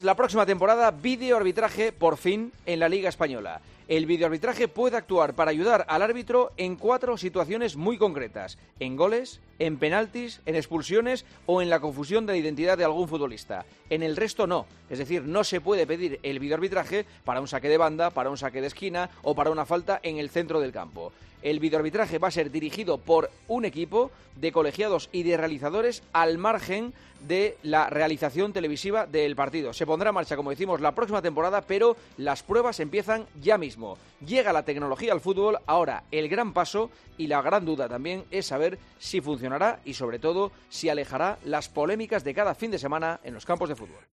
La introducción del videoarbitraje al fútbol español, en el comentario de Juanma Castaño en 'Herrera en COPE'